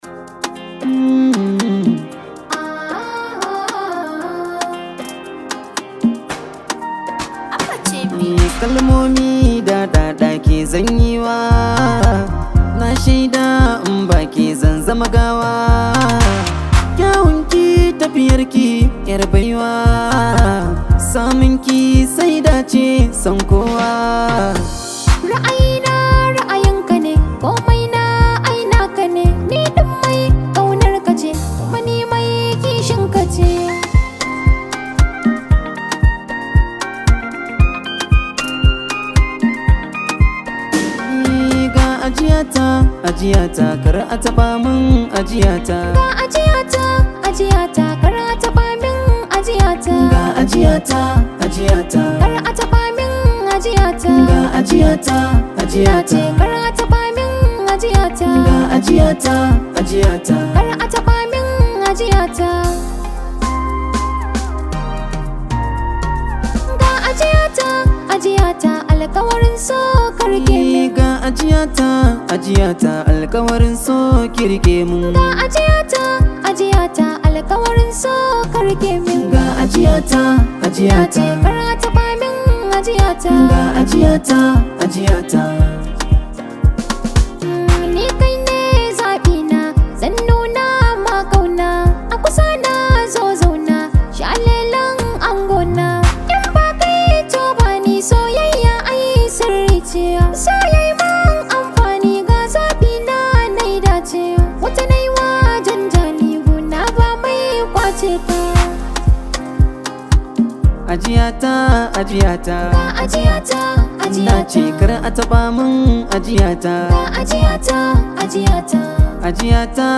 hausa music track
an Arewa rooted song